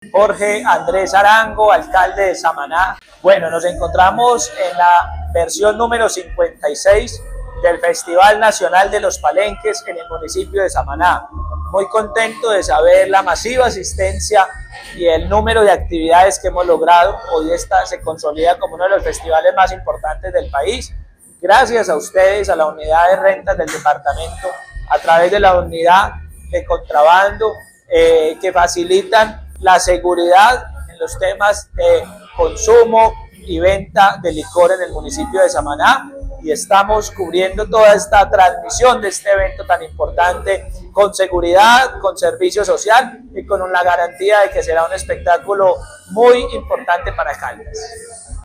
Jorge Andrés Arango, alcalde de Samaná.
Alcalde-de-Samana-Jorge-.mp3